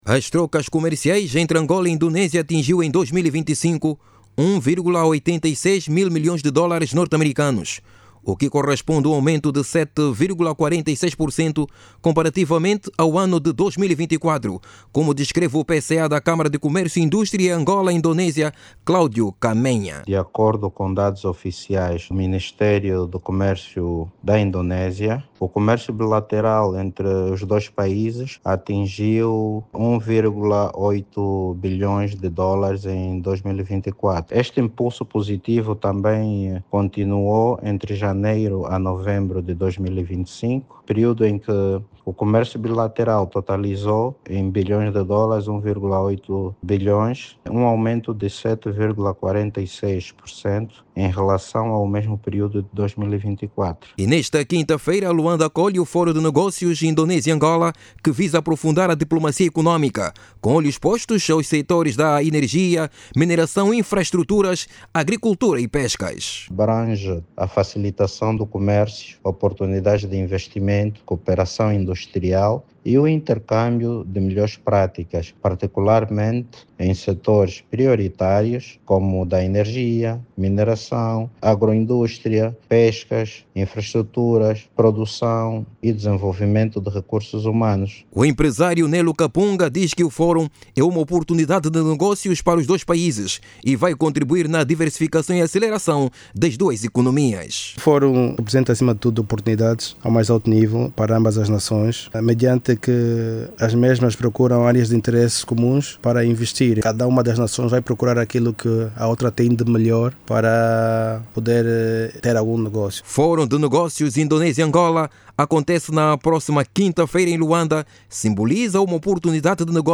Ouça o desenvolvimento desta matéria na voz do jornalista